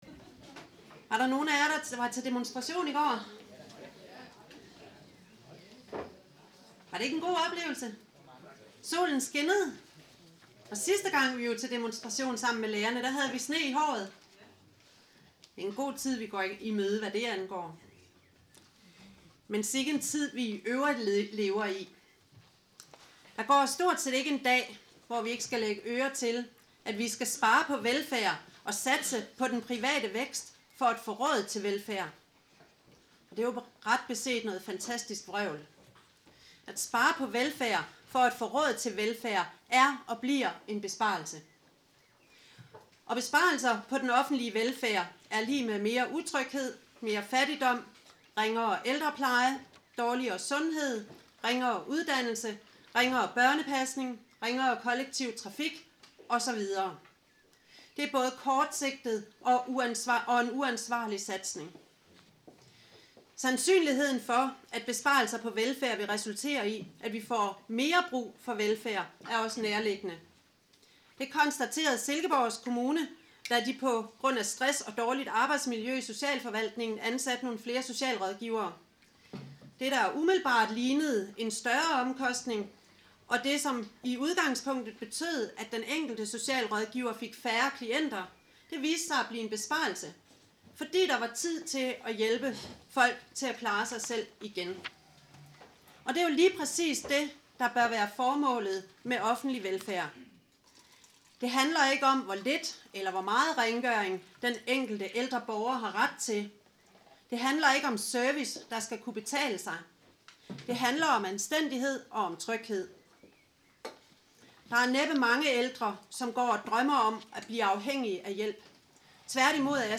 Generalforsamlingen vedtog en udtalelse om lærerkonflikten, som interesserede kan læse her .